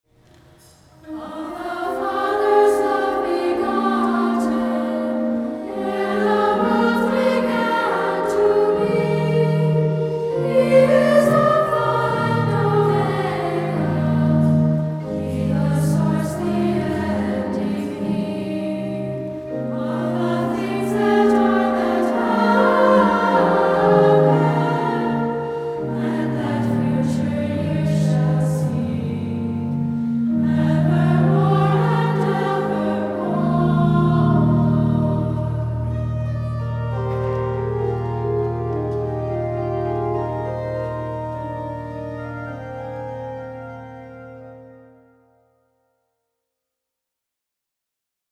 Additional information about Hymn 668 Download the PDF version of this SATB setting—in English or in Latin—by visiting the following article: PDF Download • “Corde Natus Ex Parentis” w/ Modern Harmonies (4 pages) .